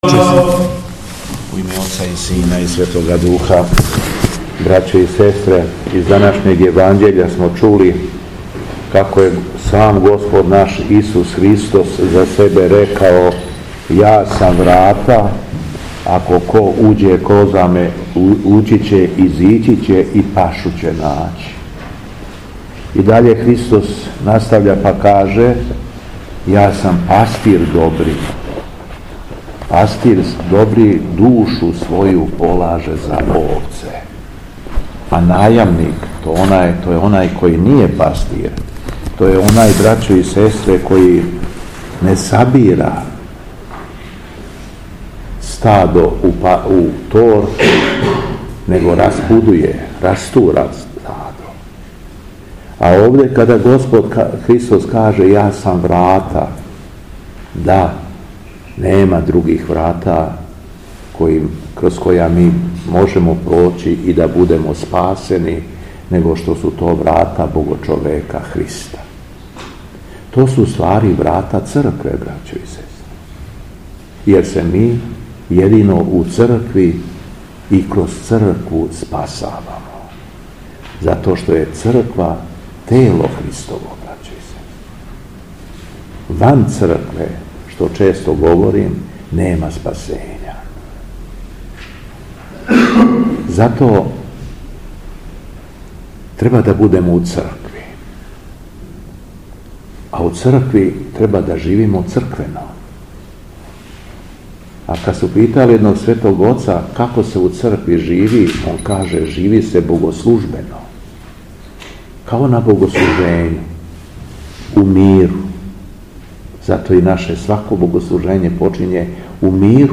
Беседа Његовог Високопреосвештенства Митрополита шумадијског г. Јована
После прочитаног Јеванђеља Високопреосвећени владика се обратио верном народу беседом рекавши: